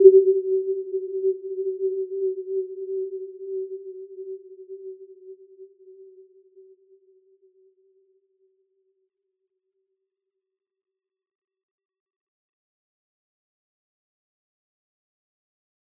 Warm-Bounce-G4-f.wav